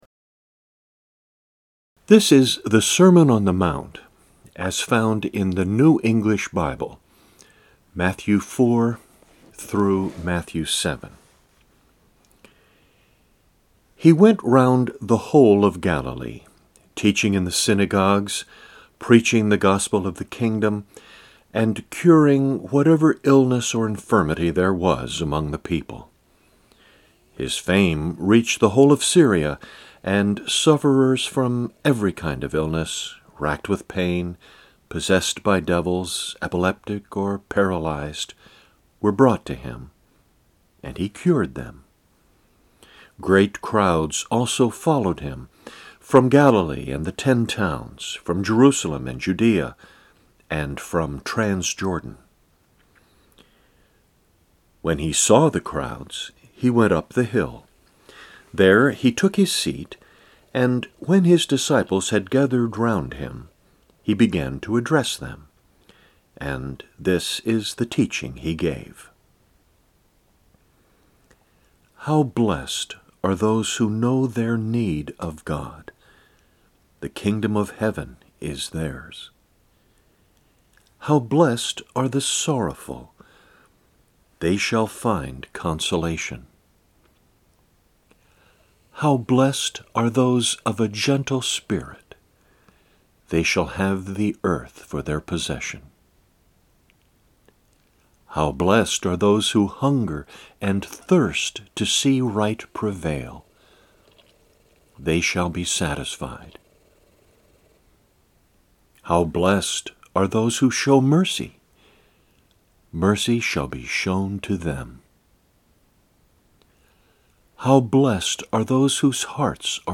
Eight recordings of different translations of Matthew 4-7.
Sermon-NEB.mp3